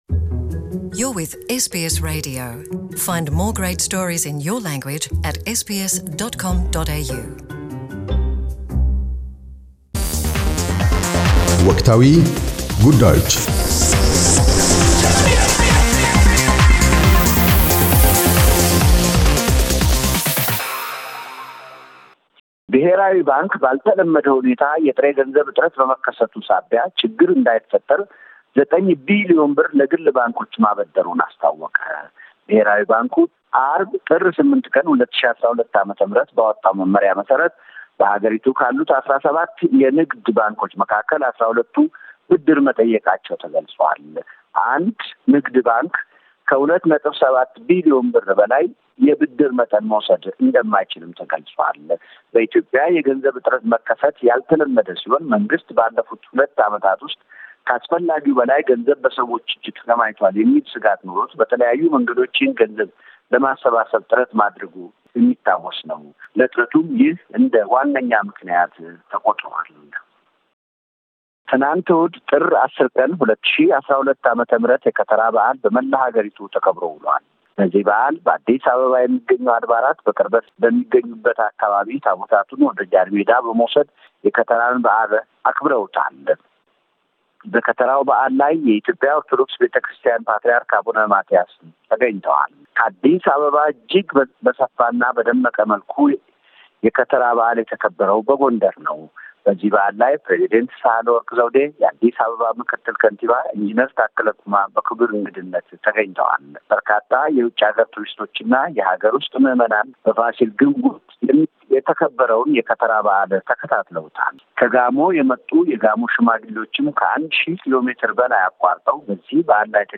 አገርኛ ሪፖርት - ባልተለመደ መልኩ ኢትዮጵያ ውስጥ የጥሬ ገንዘብ እጥረት መድረሰን ተከትሎ ዘጠኝ ቢሊየን ብር ለግል ባንኮች ማበደሩን ቀዳሚ ትኩረቱ አድርጓል።